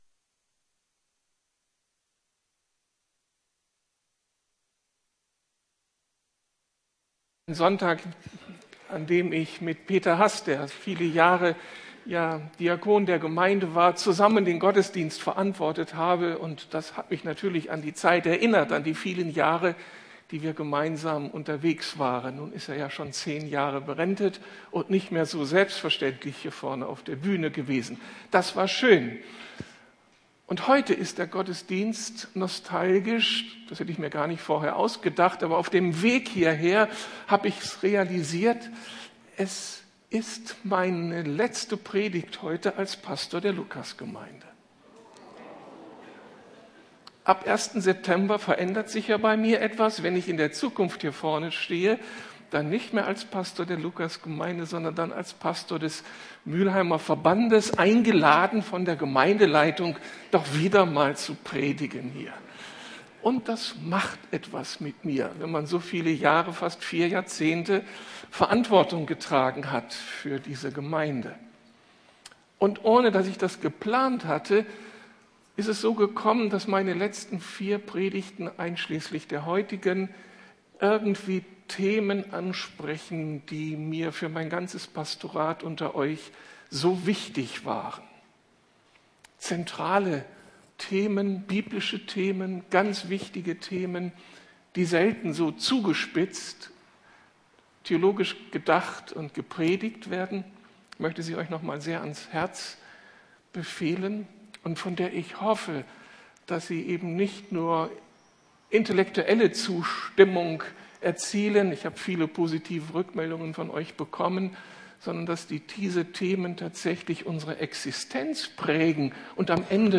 Wir lassen uns nicht einschüchtern, sondern ergreifen unser Erbe ~ Predigten der LUKAS GEMEINDE Podcast